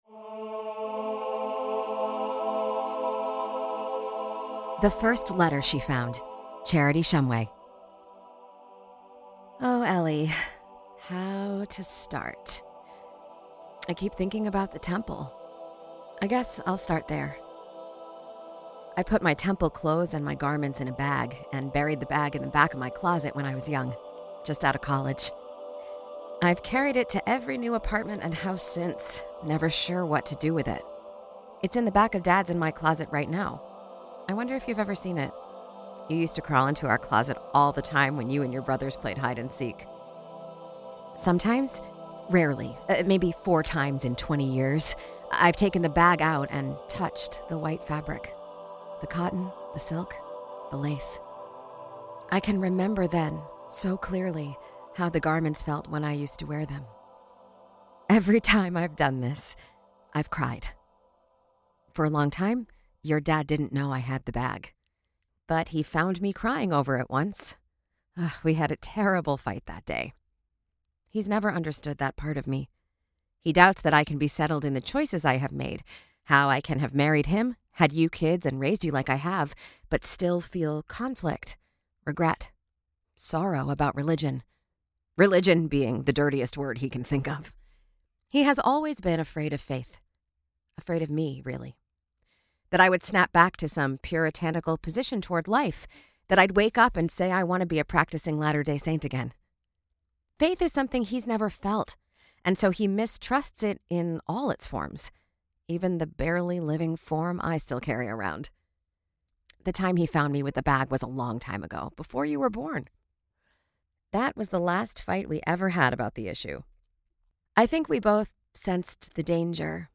The First Letter She Found by Charity Shumway: Audio Story